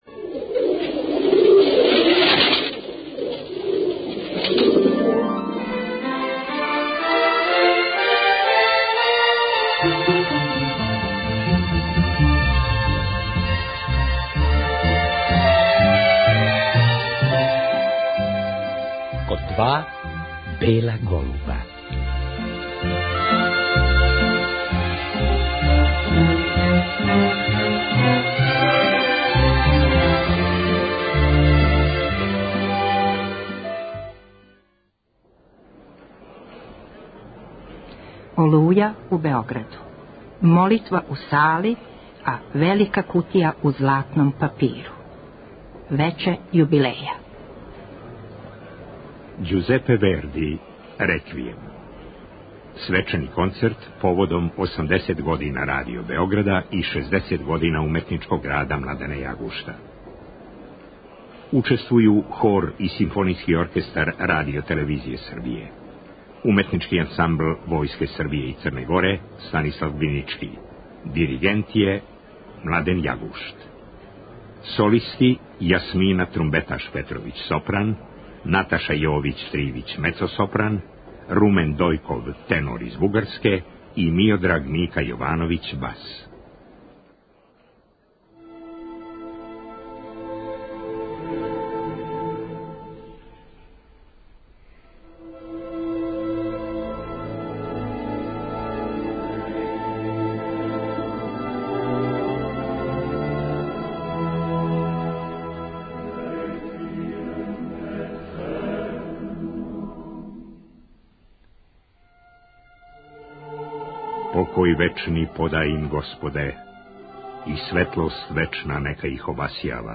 Реприза
Непосредно после концерта разговали смо са дирегентом, који нам је одмах рекао како није стизао ни банану да поједе док је припремао концерт.